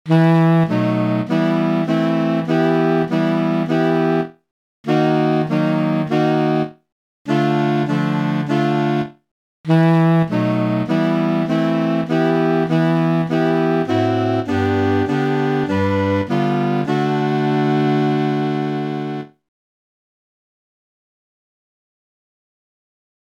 Key written in: E Minor
How many parts: 4
Type: Barbershop
All Parts mix: